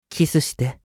青年ボイス～恋愛系ボイス～
☆★☆★恋愛系☆★☆★